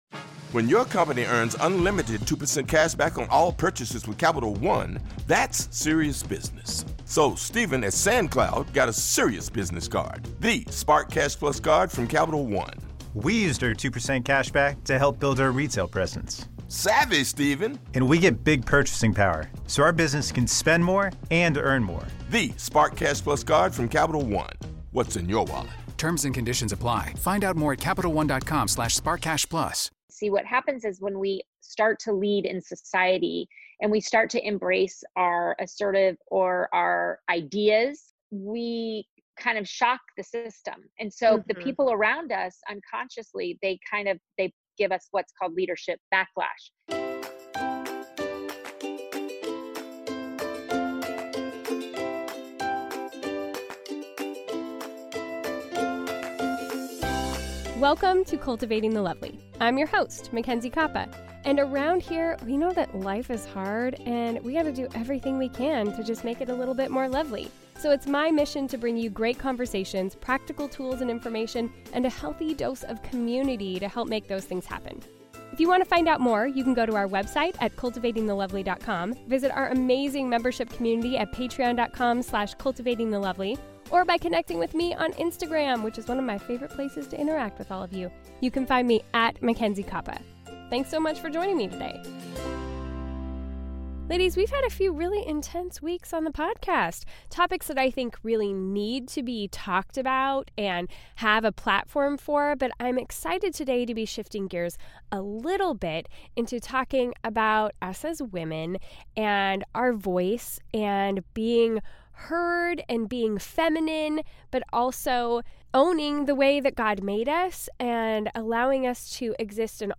This conversation is for ALL women, not just those who work in or out of the home, so regardless of your season of life, we hope you will get something out of this episode!